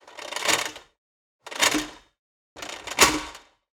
Checking progress is a necessary part of gym-based wellness. This gym sound effect gives you the necessary cue as the dial slides and drops to each marker.